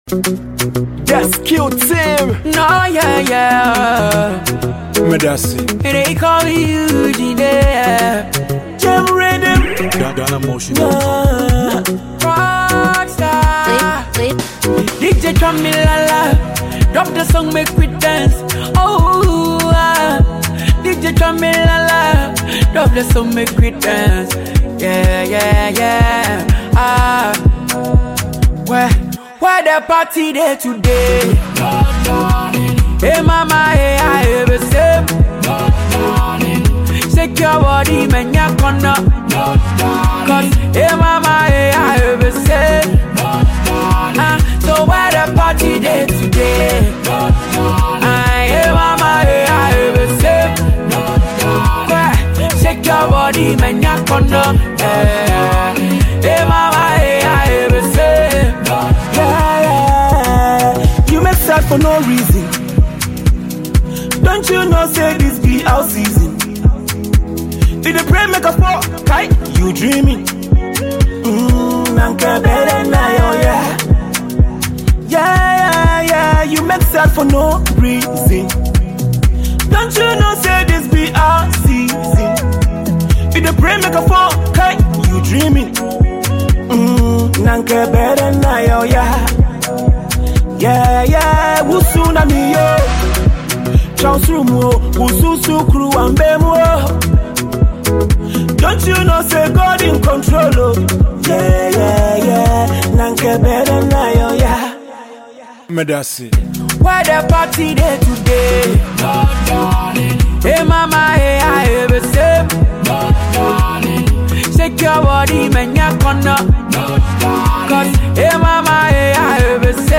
music duo